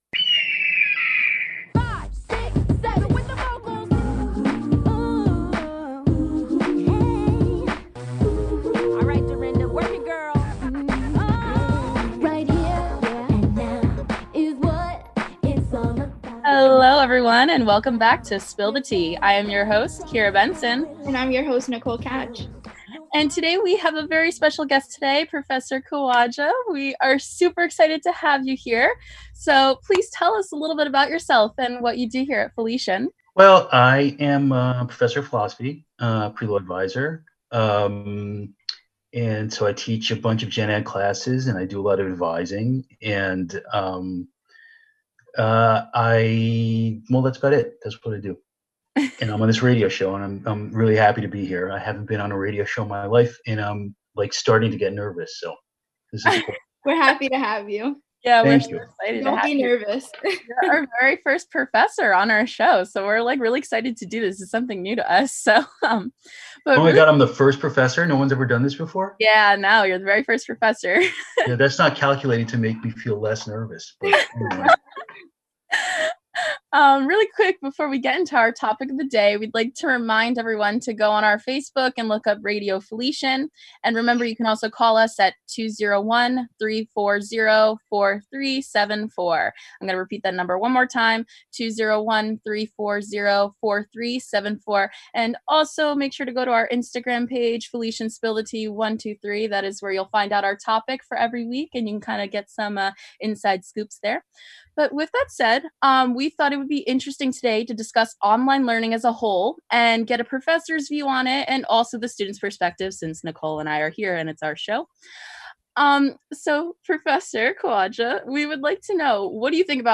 Here’s a half-hour interview with me on Radio Felician University, on the pros and cons of online learning during the coronavirus crisis.